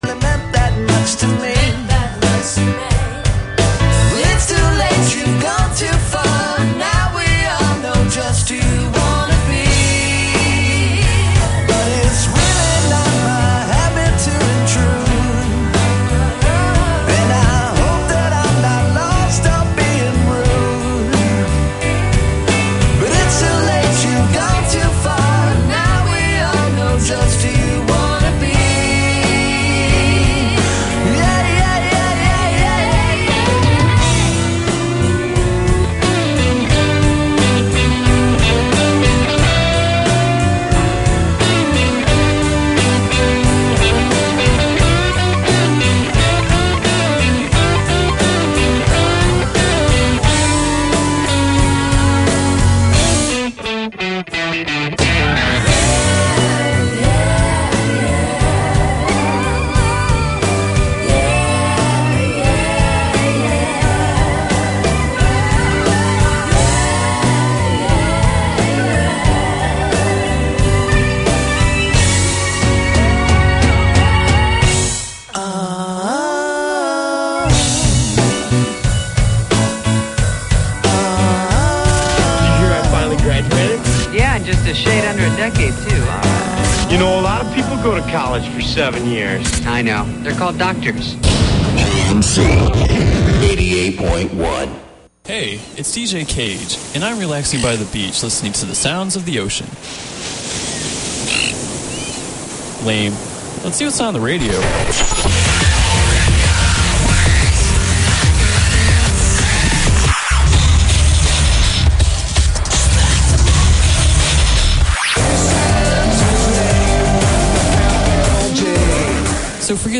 porcupinetreeinterview.mp3